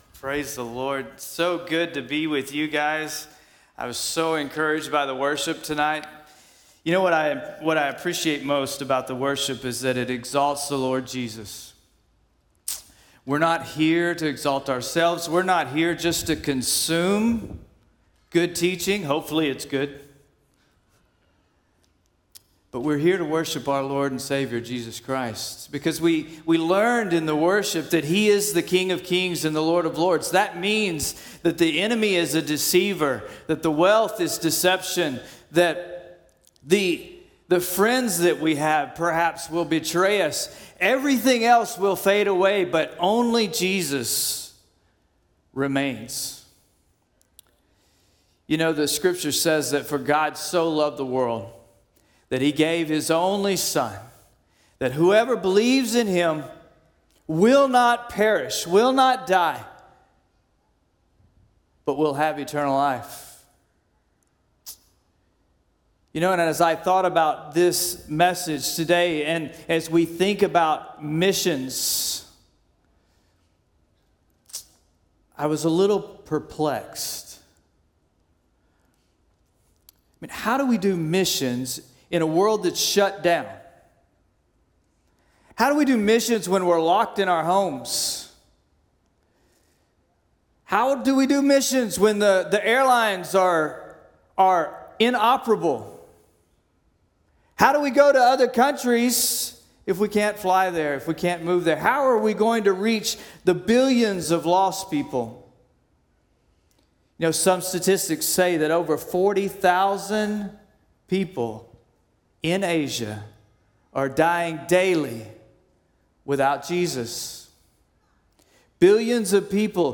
All Sermons Missions in a Post-Pandemic World April 9